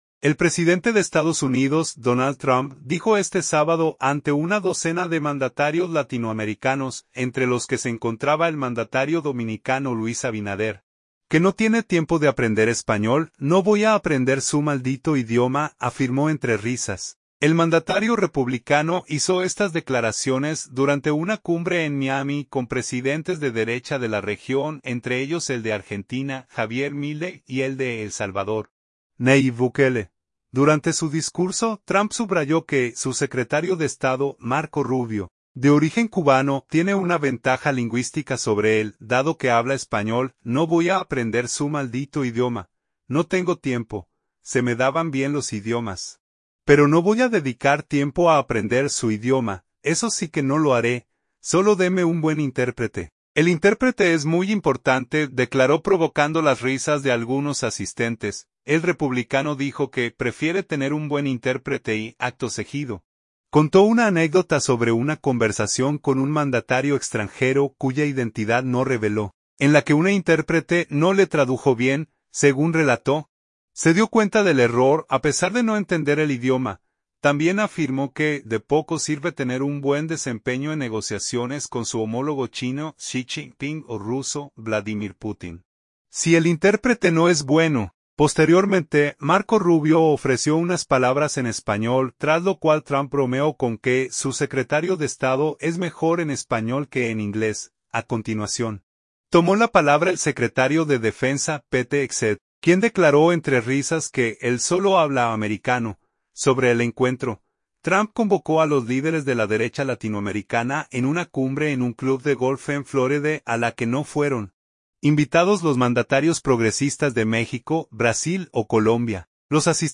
El presidente de Estados Unidos, Donald Trump, dijo este sábado ante una docena de mandatarios latinoamericanos, entre los que se encontraba el mandatario dominicano Luis Abinader, que no tiene tiempo de aprender español: "No voy a aprender su maldito idioma", afirmó entre risas.
El mandatario republicano hizo estas declaraciones durante una cumbre en Miami con presidentes de derecha de la región, entre ellos el de Argentina, Javier Milei, y el de El Salvador, Nayib Bukele.
"No voy a aprender su maldito idioma. No tengo tiempo. Se me daban bien los idiomas, pero no voy a dedicar tiempo a aprender su idioma. Eso sí que no lo haré. Solo denme un buen intérprete. El intérprete es muy importante", declaró provocando las risas de algunos asistentes.